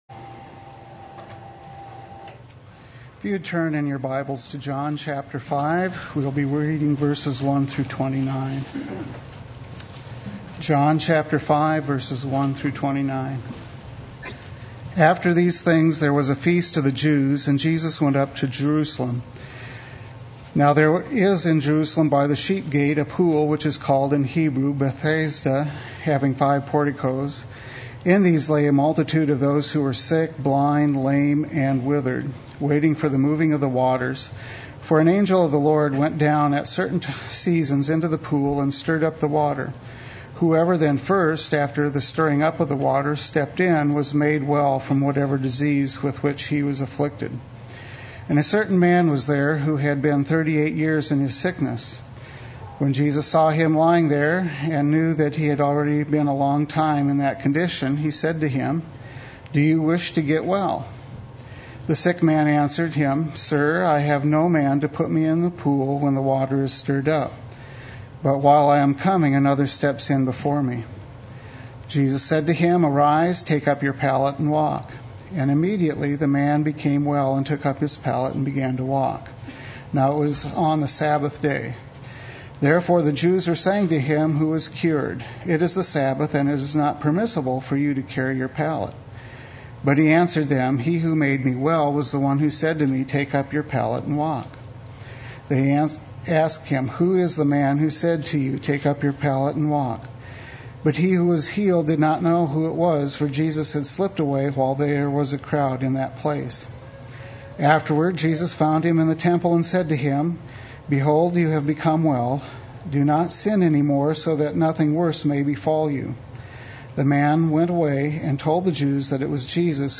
Play Sermon Get HCF Teaching Automatically.
My Father is Working Sunday Worship